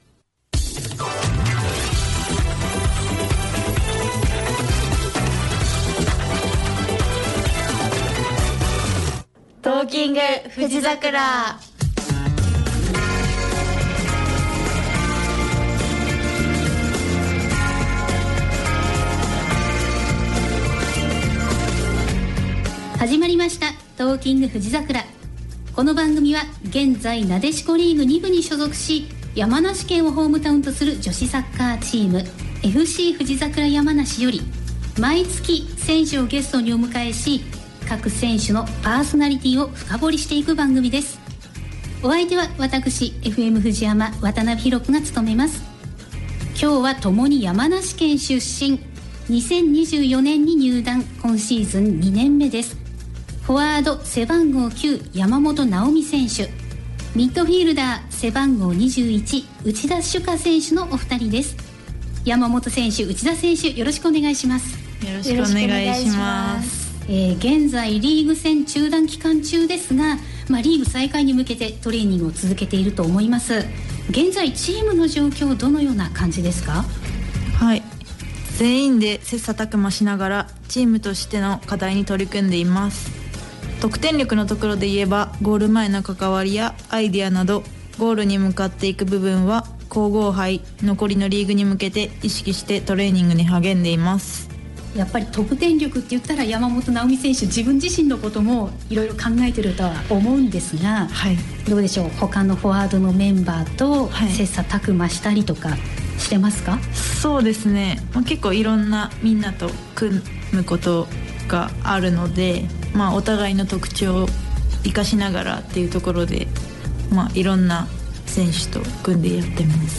答えは…楽しく番組内で喋ってます♪） ※ラスト曲は版権の都合上カットしています。